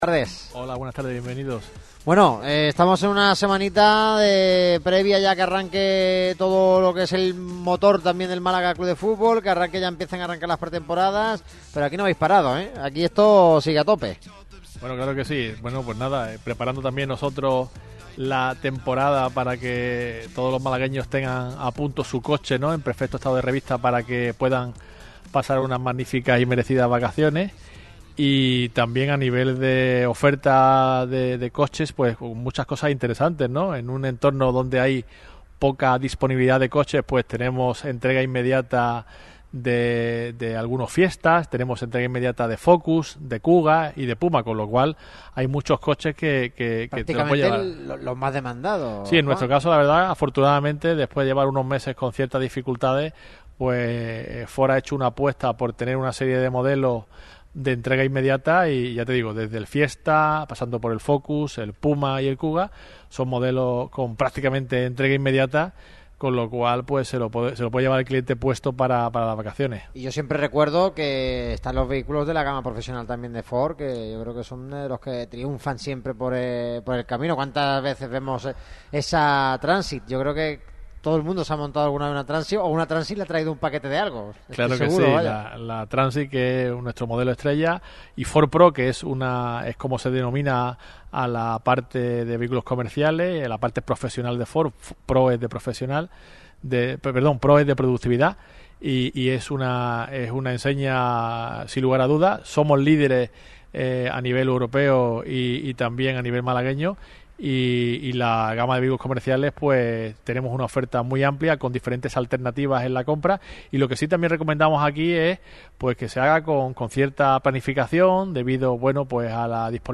Radio Marca Málaga regresa a Ford Garum Motor, concesionario oficial de la marca en la capital costasoleña.